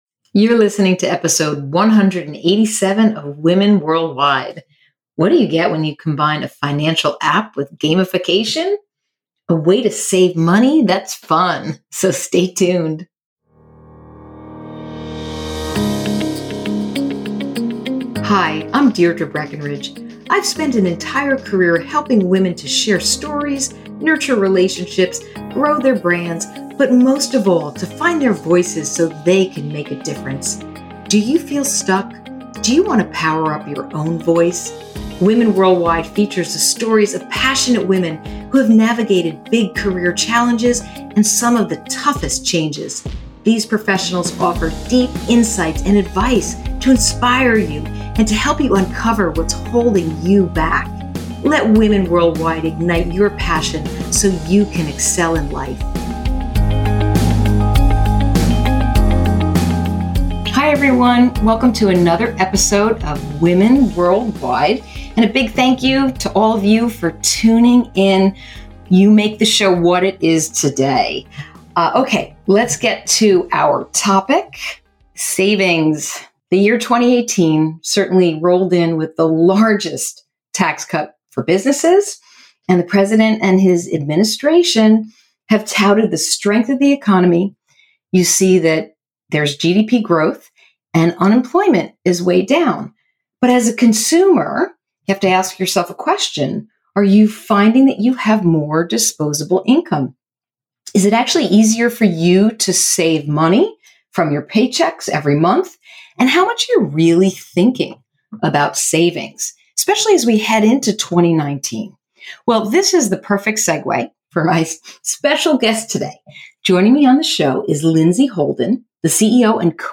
This is a great interview where a young entrepreneur shares how innovation can change our futures.